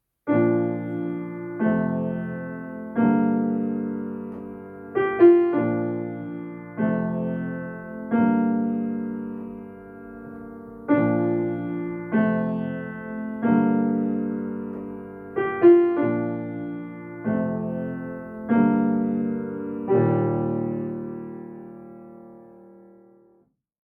Piano Solo
10 minimalist pieces for Piano.